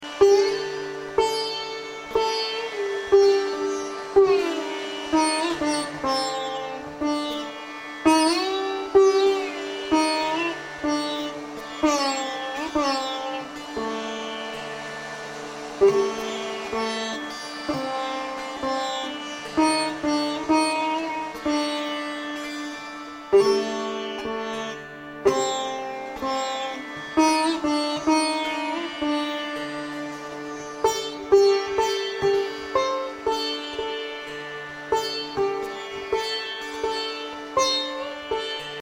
Healing/Meditation/Yoga Music
meditation music
sitar music , soothing music